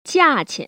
[jià‧qian] 지아치앤